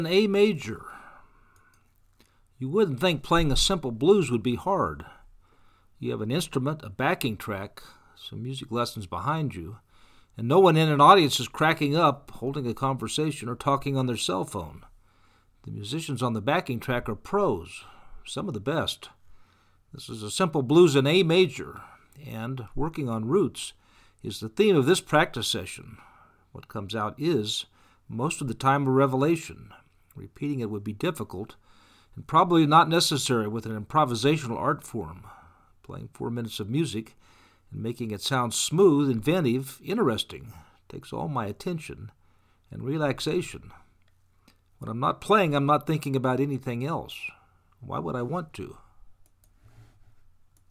Blues in A Major Practice Session, April 13, 2020
You have an instrument, a backing track, some music lessons behind you, and no one in an audience is cracking up, holding  a conversation, or talking on their cell phone. The musicians on the backing track are pros, some of the best. This is a simple Blues in A major , and, working on roots is the theme of this practice session.
blues-in-A-major-1.mp3